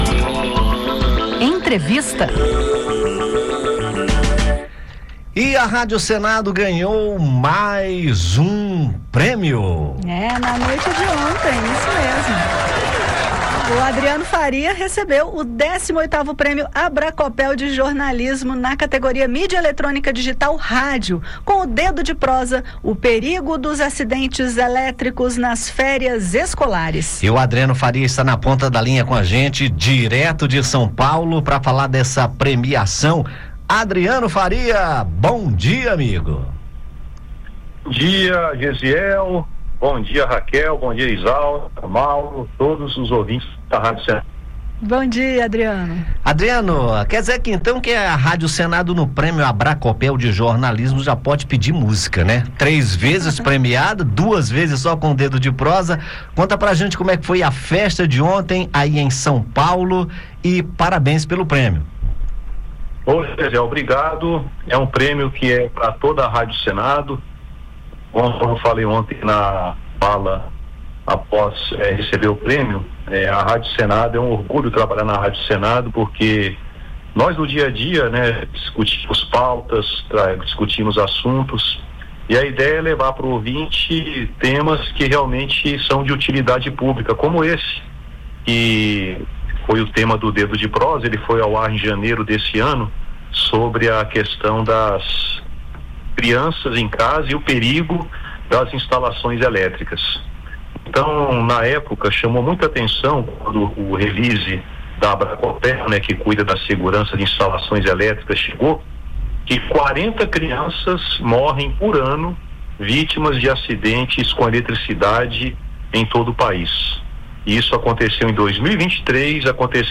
Direto de São Paulo